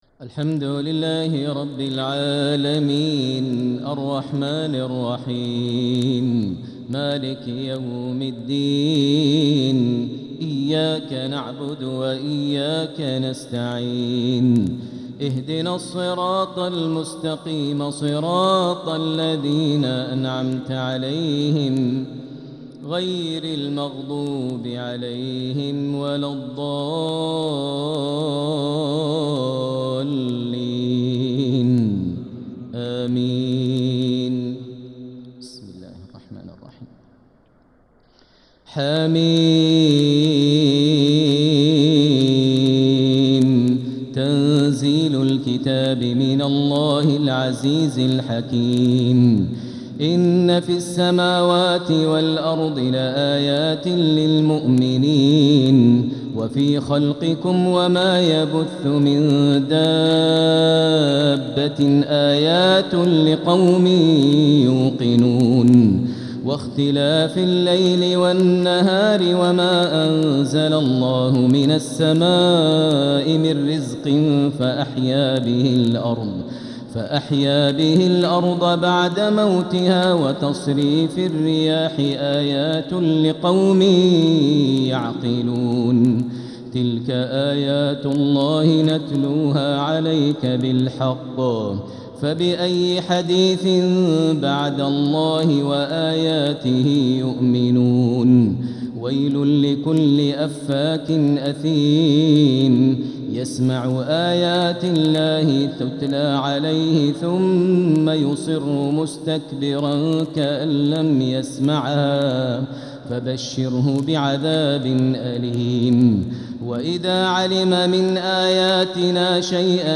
تلاوة من سورة الجاثية الى محمد كاملة | تهجد ليلة 26 رمضان 1446هـ > تراويح 1446 هـ > التراويح - تلاوات ماهر المعيقلي